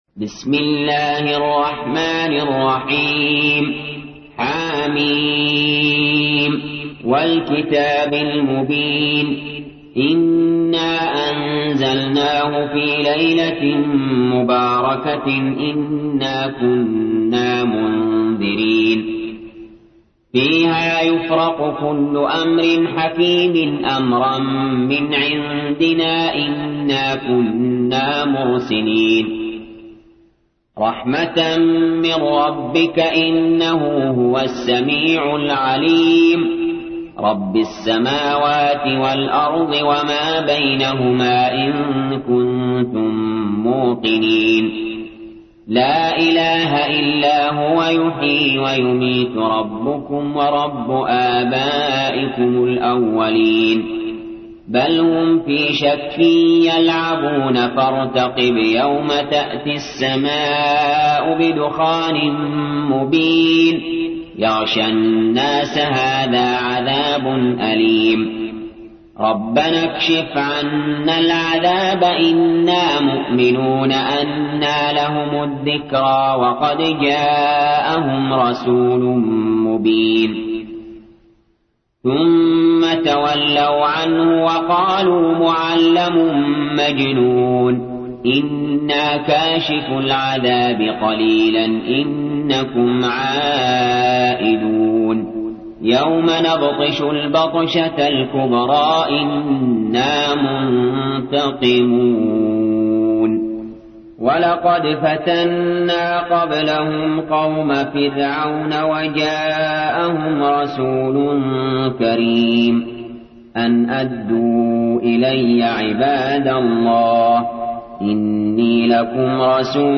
تحميل : 44. سورة الدخان / القارئ علي جابر / القرآن الكريم / موقع يا حسين